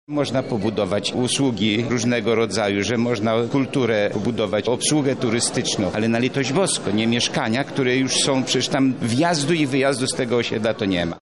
„Tam jest tłok ponieważ jest już za dużo mieszkań na tym terenie” – mówi radny Jan Gąbka